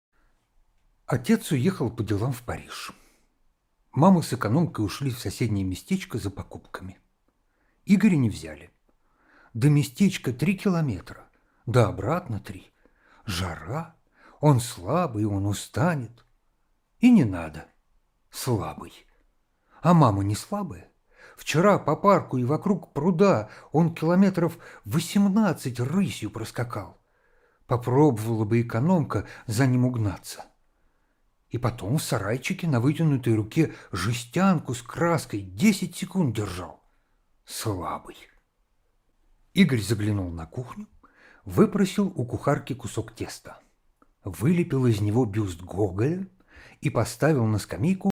Игорь-Робинзон - аудио рассказ Саши Черного - слушать онлайн